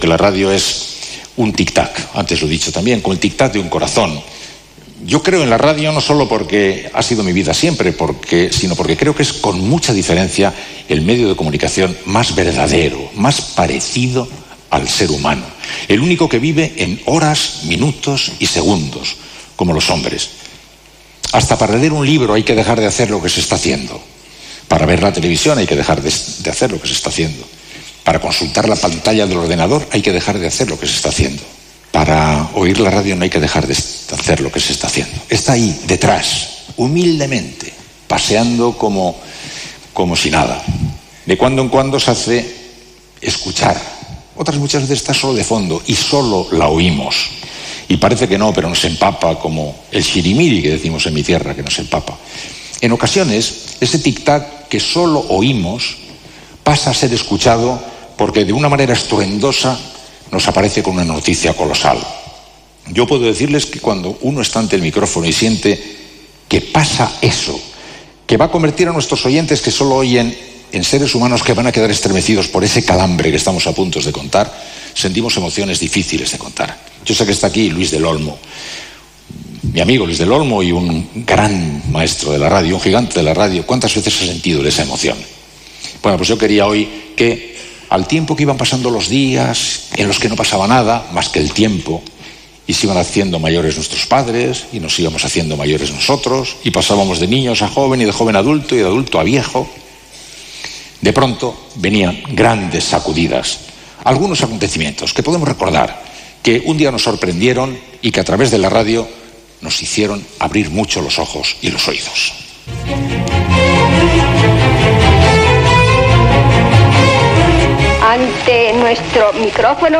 Gala del 90 aniversari de Ràdio Barcelona feta des del Palau de la Música. Àudios històrics de Ràdio Barcelona i la Cadena SER.
Entreteniment